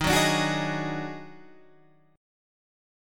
D# Minor Major 7th Double Flat 5th